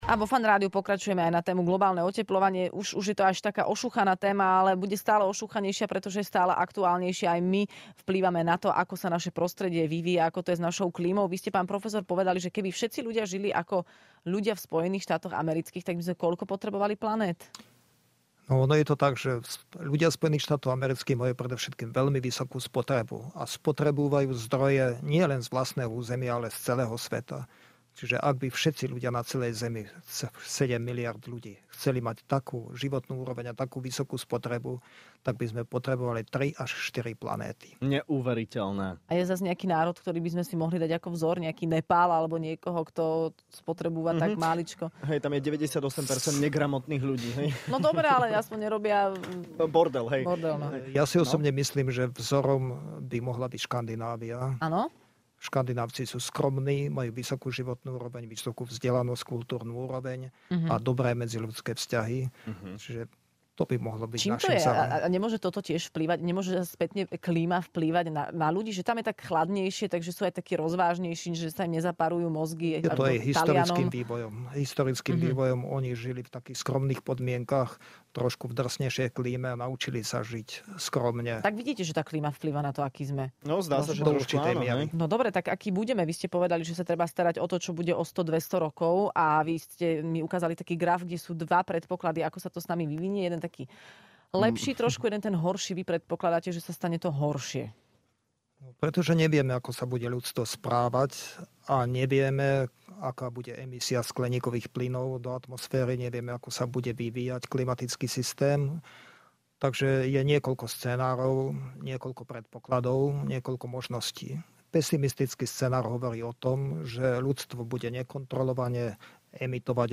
Počasie si s nami stále robí čo chce, a tak sme si do štúdia zavolali klimatológa